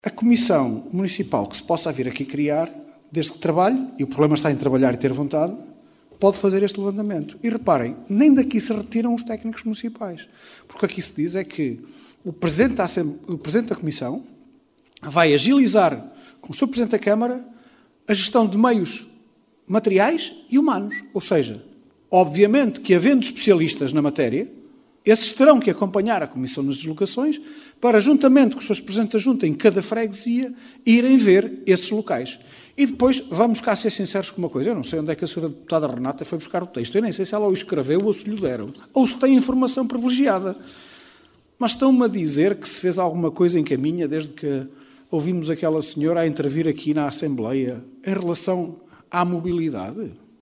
Excertos da Assembleia Municipal extraordinária de 24 de Novembro.